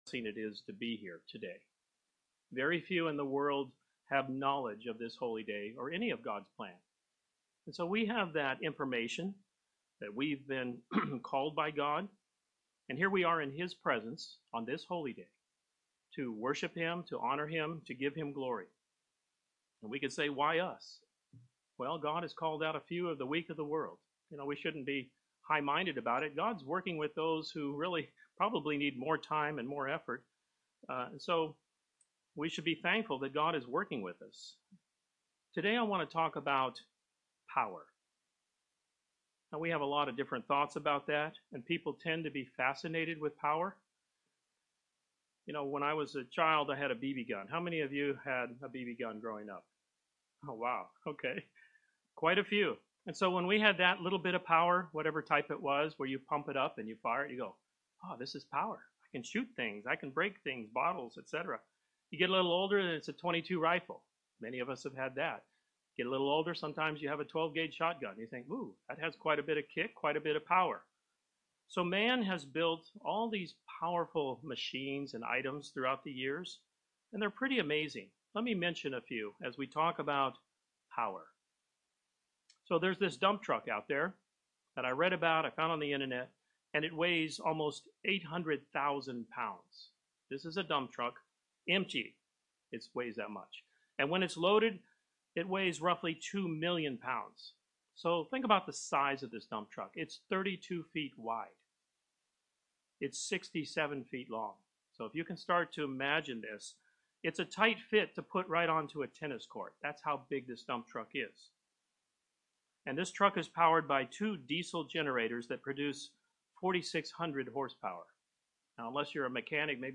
Sermons
Given in St. Petersburg, FL